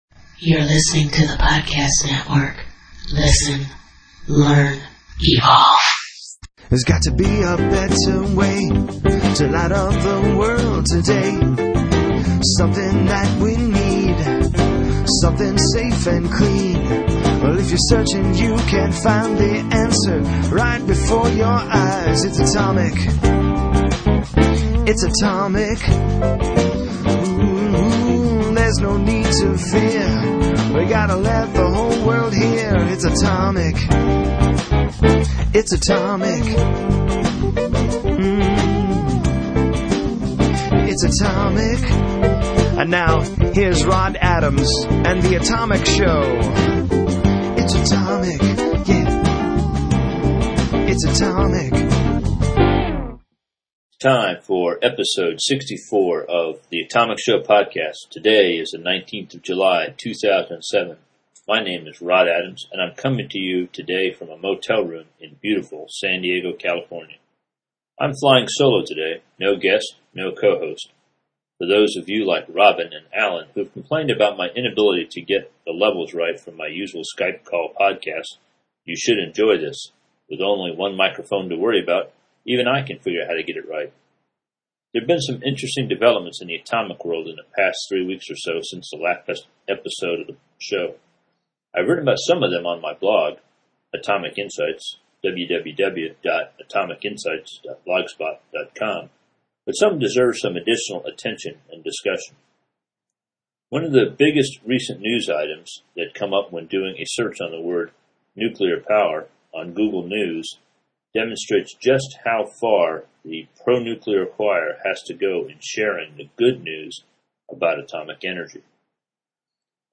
This is my first show as a solo podcaster. On the show I talk about the recent earthquake in Japan, the GAO’s sting operation targeting the Nuclear Regulatory Commission, Kazakhstan’s investment in Westinghouse, an interesting blog post titled Rethinking Nuclear, and my desire to create some new atomic evangelists.